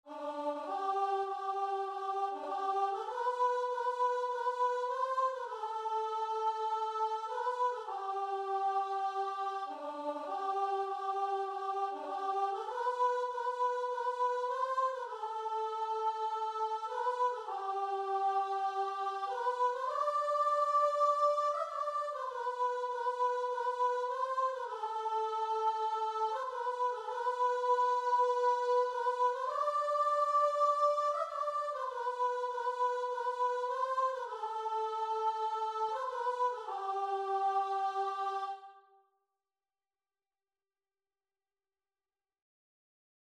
4/4 (View more 4/4 Music)
Classical (View more Classical Guitar and Vocal Music)